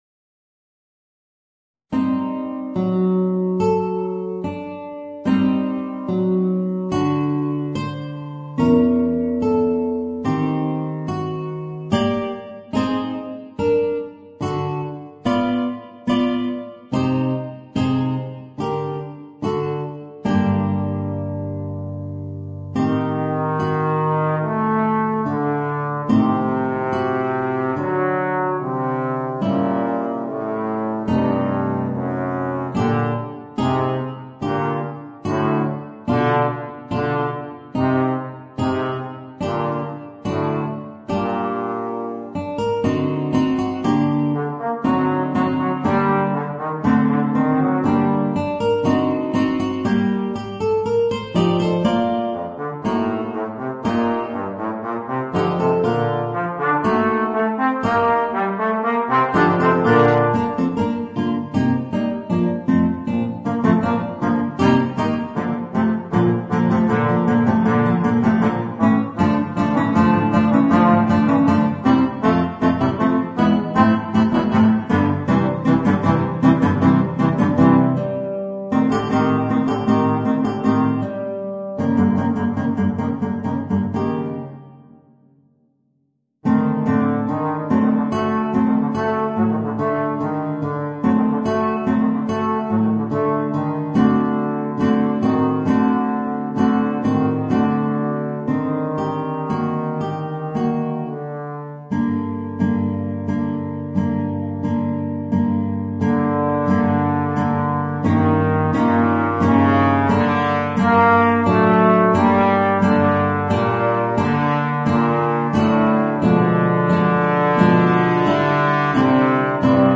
Voicing: Bass Trombone and Guitar